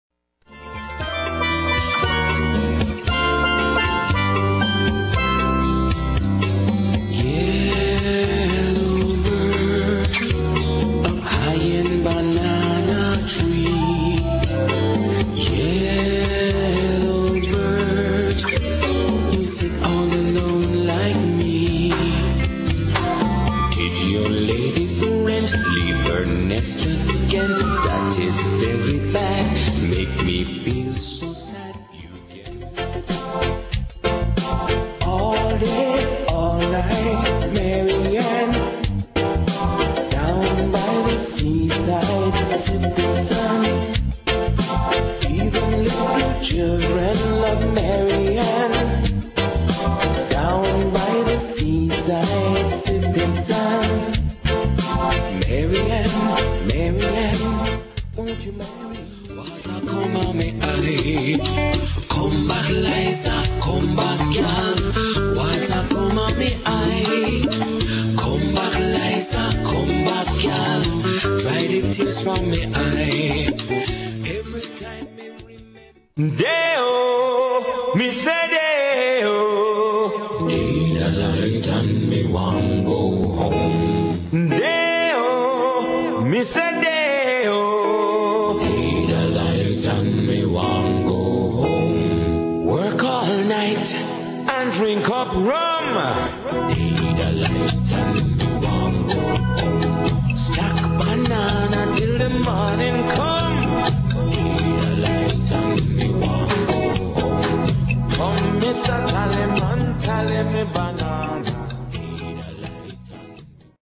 The Caribbean's hottest reggae singer